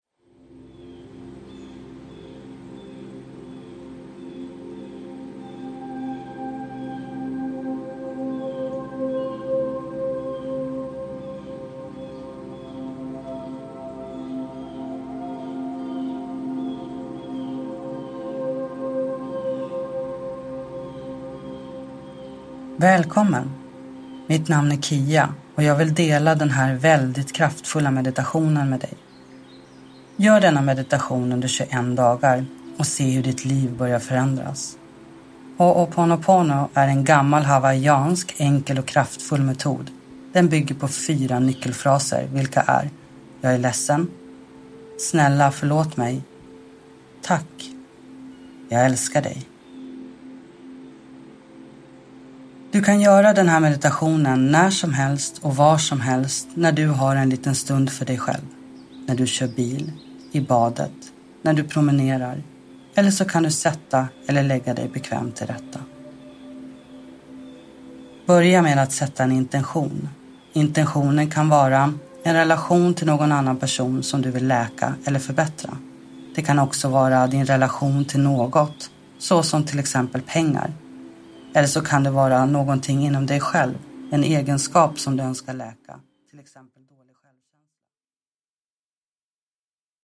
Hooponopono, guidad meditation – Ljudbok